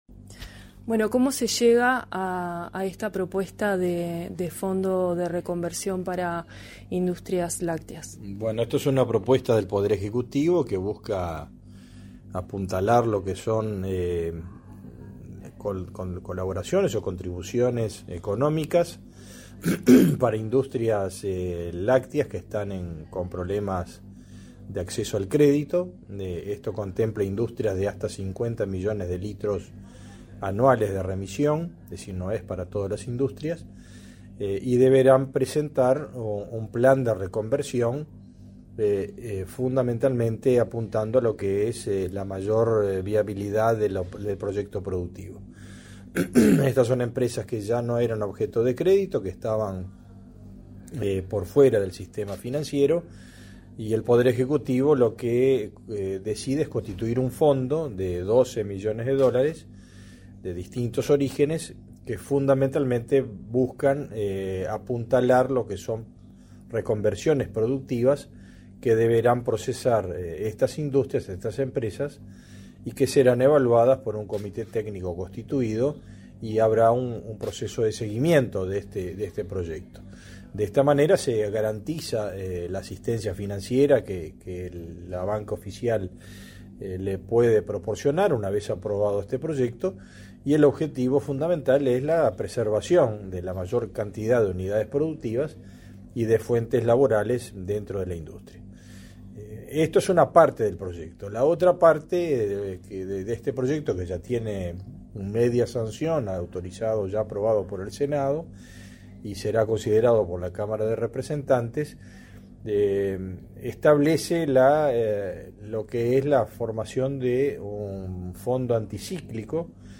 Entrevista al ministro de Ganadería, Agricultura y Pesca, Fernando Mattos